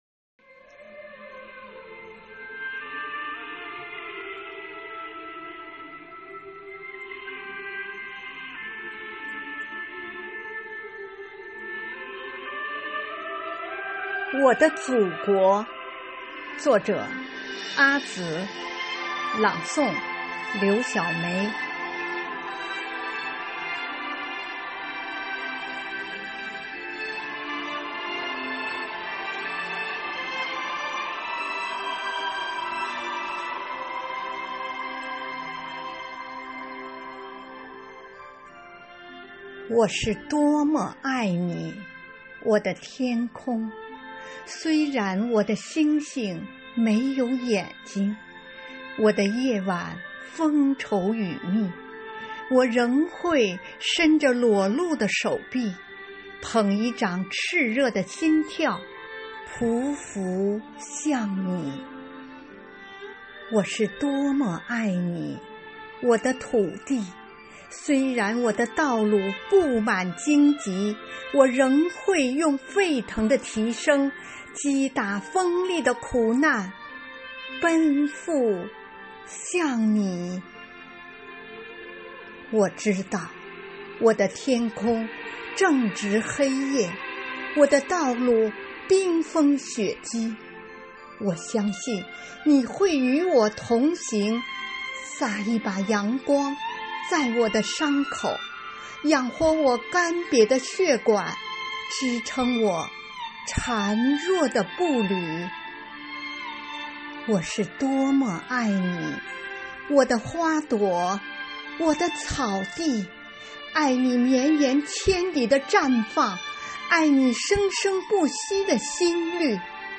“生活好课堂幸福志愿者广外科普大学朗读服务（支）队”是“生活好课堂幸福志愿者朗读服务队”的第二支队伍，简称“广外科普大学朗读支队”。“生活好课堂”的九岁生日当晚，全体队员演绎了一场激情澎湃的朗诵会。
《我的祖国》独诵